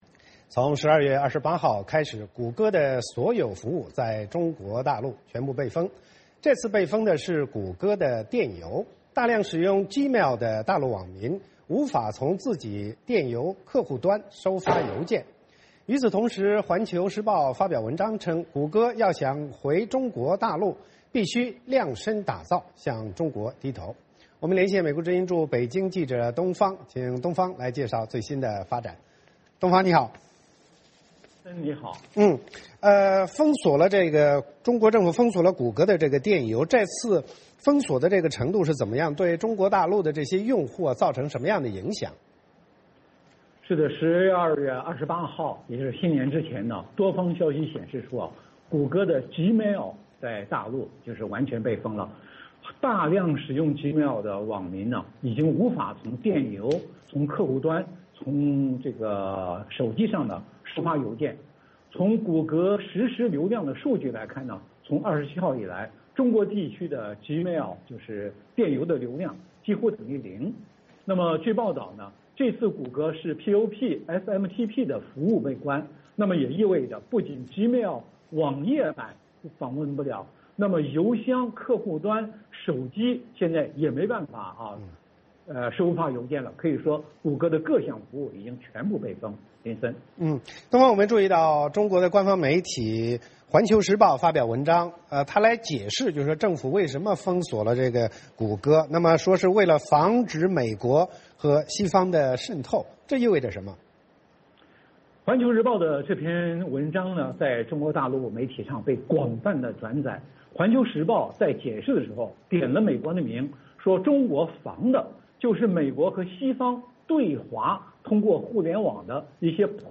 VOA连线：中国全面封锁谷歌所有服务